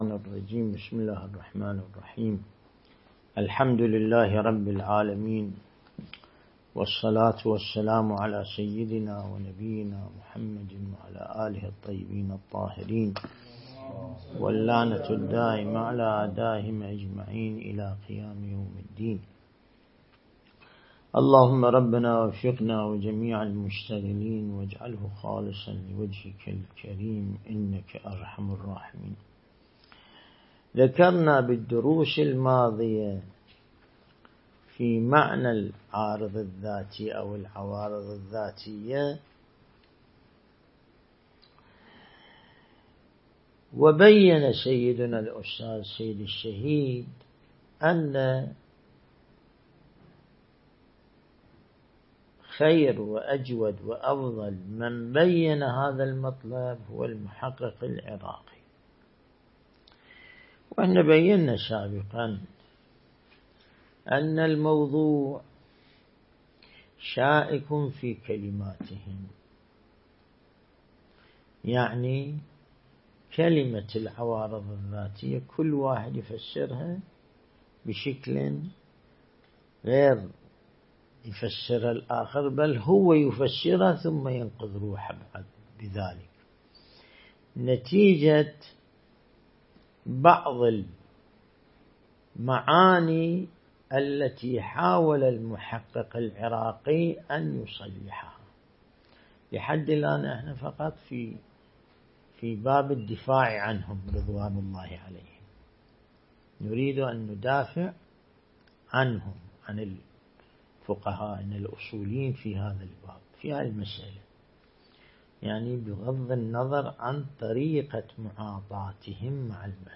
درس البحث الخارج الأصول (49) | الموقع الرسمي لمكتب سماحة آية الله السيد ياسين الموسوي «دام ظله»
النجف الأشرف